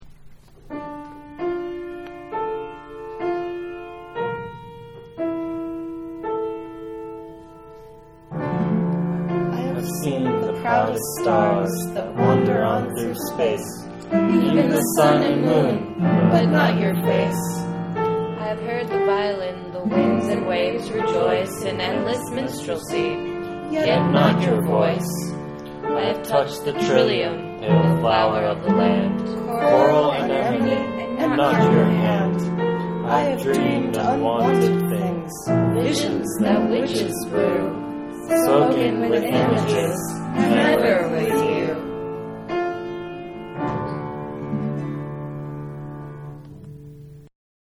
Our most multilingual poem-recording session ever. Unmastered, tracks just thrown together–but still sounding awesome.